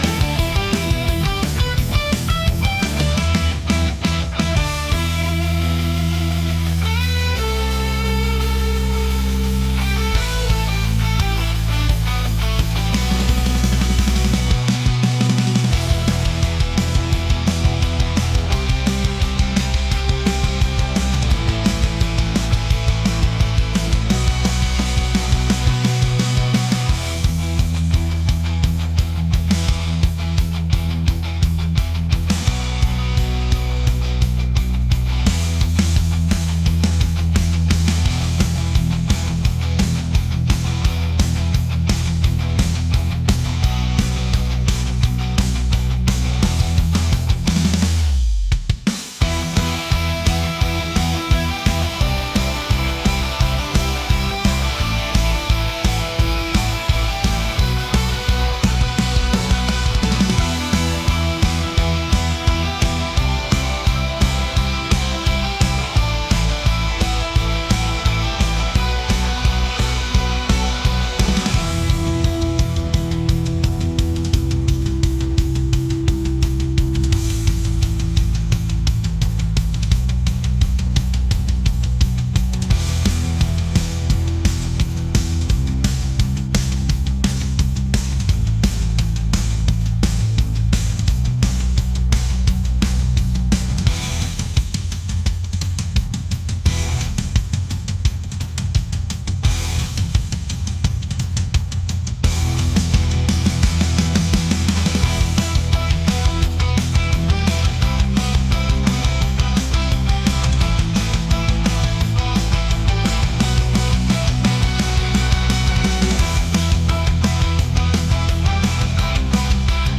rock | energetic